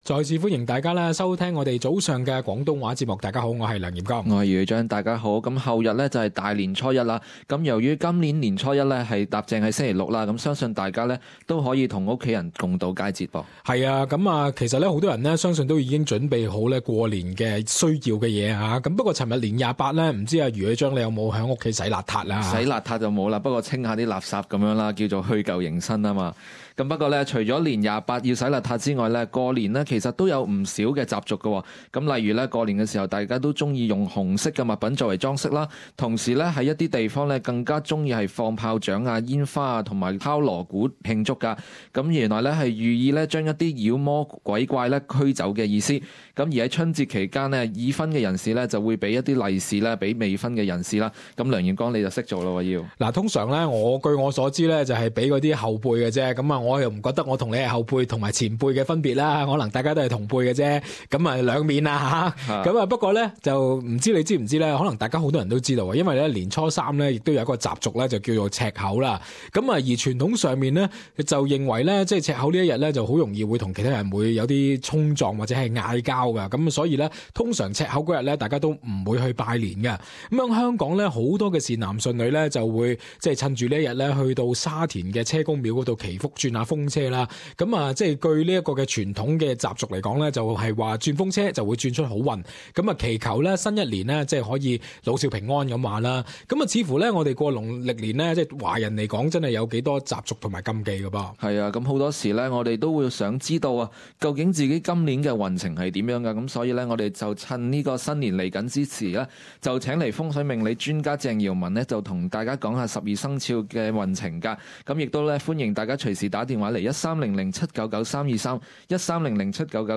Talkback - CNY and Fung Shui